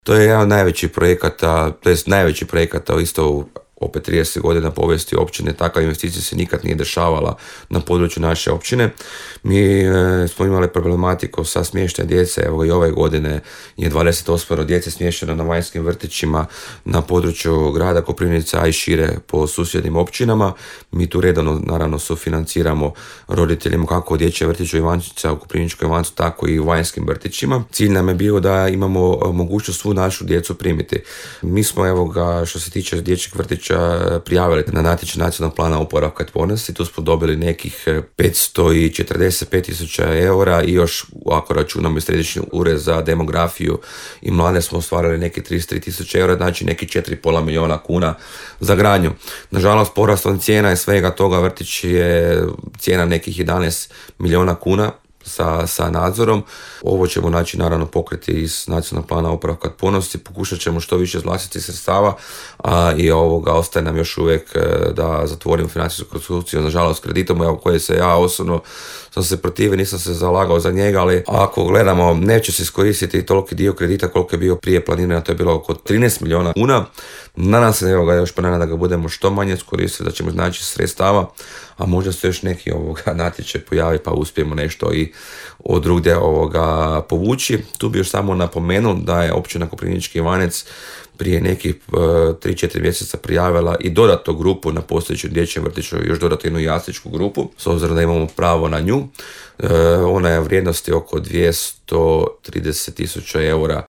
Načelnik Općine Koprivnički Ivanec Zoran Vrabelj, za Podravski radio je 2023. godinu ocjenio uspješnom. Govoreći o projektima koji su ju obilježili, spomenuo je početak gradnje Dječjeg vrtića, izdvojivši ga kao kapitalni projekt;